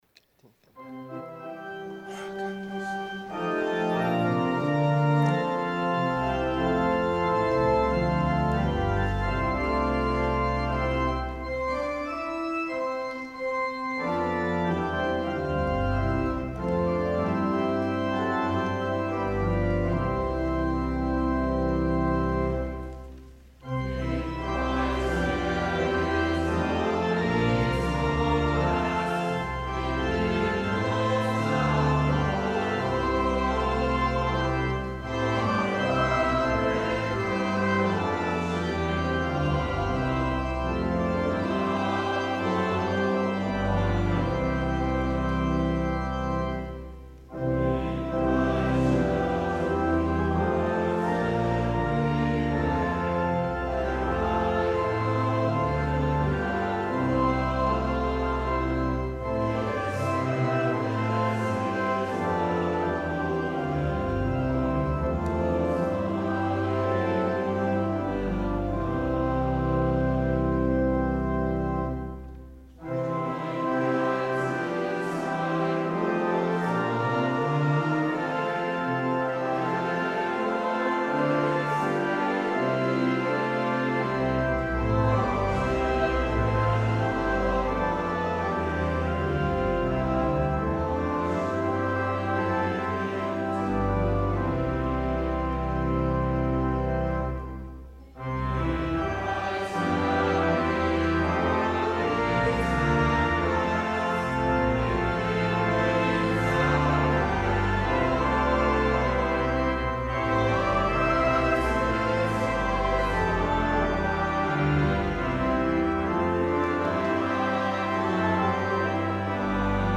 Congregation; Chancel Choir
organ